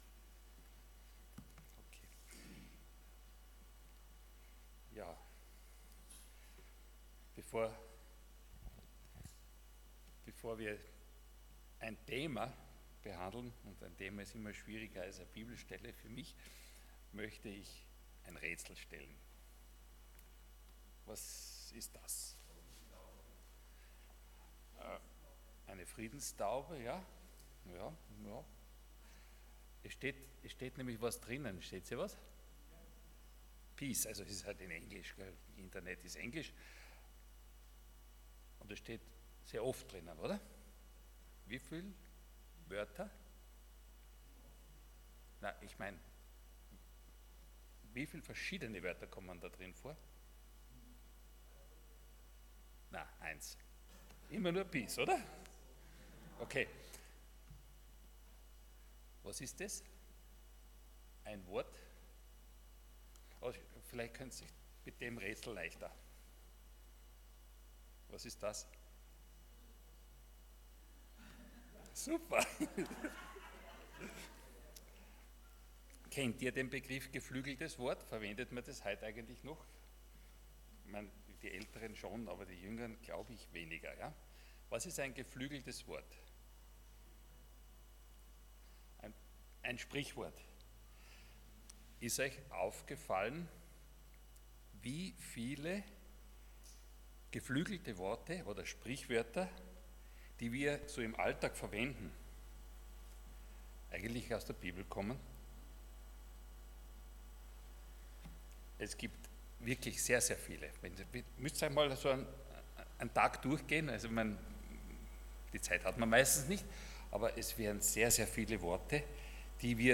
Allgemeine Predigten Dienstart: Sonntag Morgen %todo_render% Gott suchen und finden « Gottes Sehnsucht nach uns Was darf meine Nachfolge kosten?